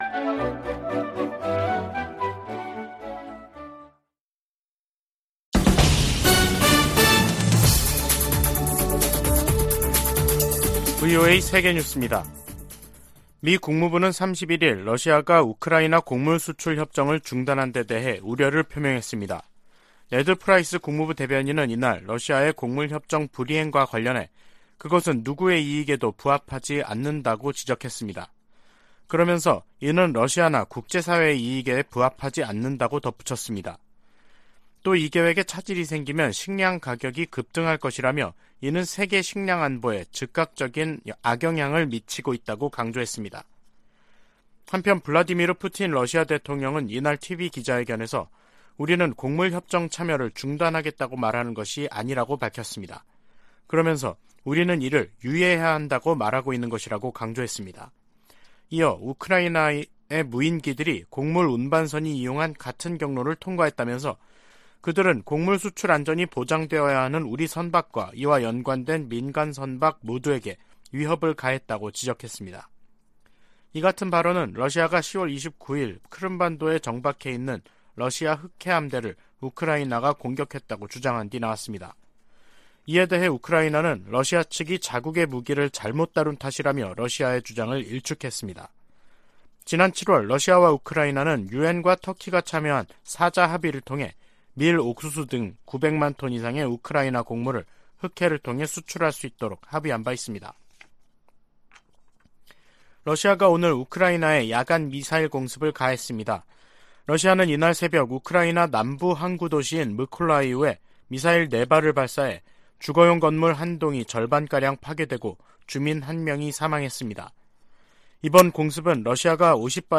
VOA 한국어 간판 뉴스 프로그램 '뉴스 투데이', 2022년 11월 1일 3부 방송입니다.